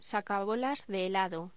Locución: Sacabolas de helado
Sonidos: Voz humana